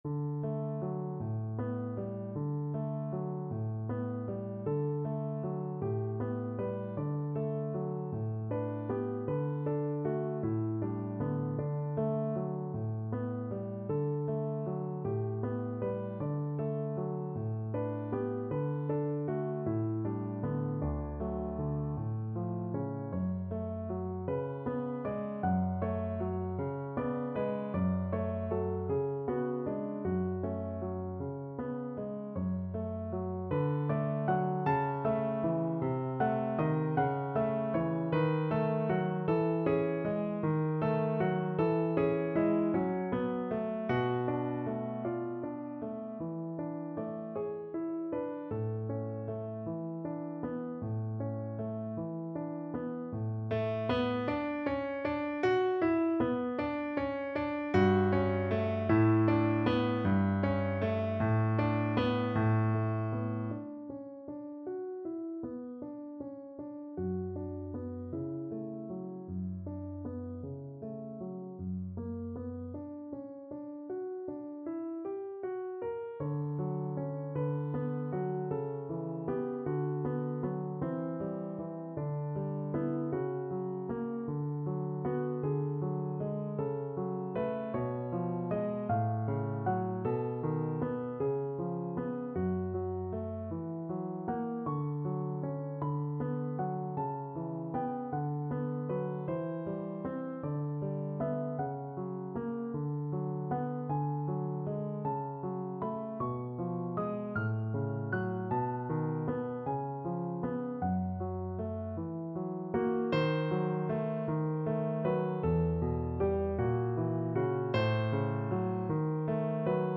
Classical Fauré, Gabriel Berceuse, Op.16 Piano version
No parts available for this pieces as it is for solo piano.
Allegro moderato .=52 (View more music marked Allegro)
D major (Sounding Pitch) (View more D major Music for Piano )
6/8 (View more 6/8 Music)
Piano  (View more Intermediate Piano Music)
Classical (View more Classical Piano Music)